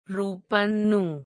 3. ɾu: pən nu: use plain n phoneme (which loses diacritic meaning)